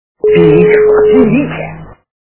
» Звуки » Из фильмов и телепередач » Золотой теленок - Пилите Шура, пилите!
При прослушивании Золотой теленок - Пилите Шура, пилите! качество понижено и присутствуют гудки.